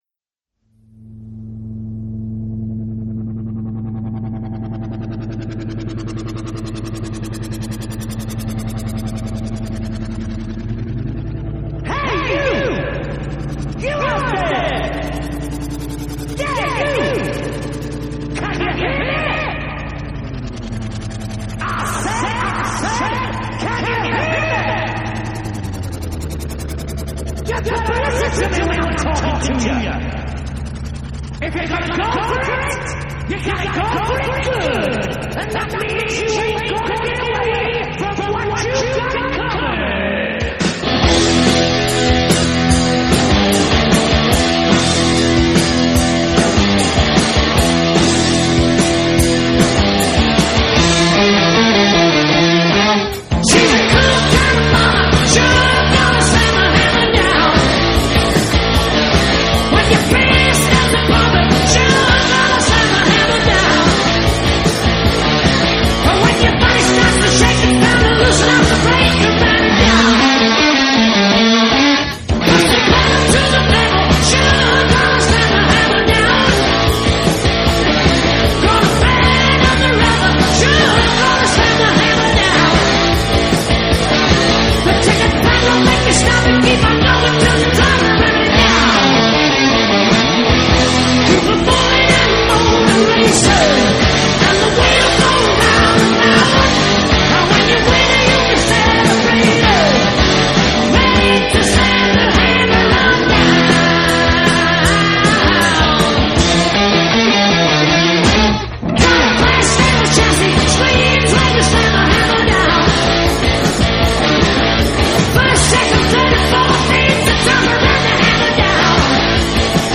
Glam Rock, Hard Rock